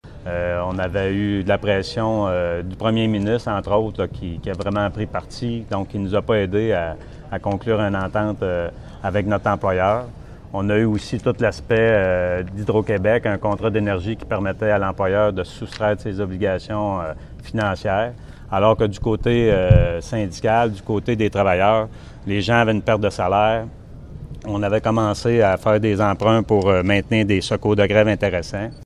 En point de presse à la suite de l’Assemblée générale de ses membres à Trois-Rivières